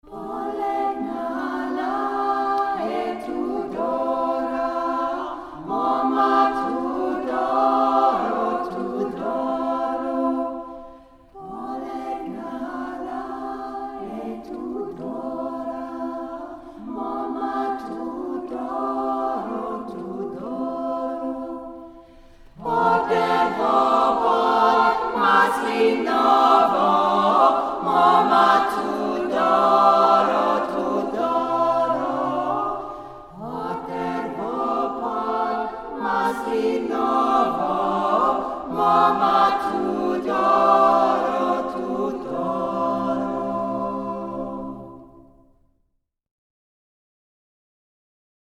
Trad. Bulgaria